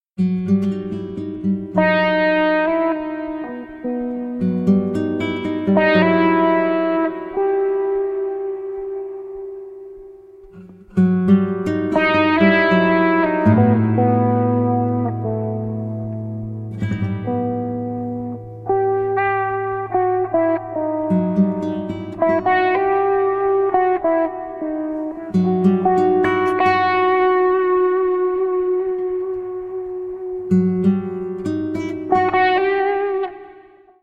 Electric guitar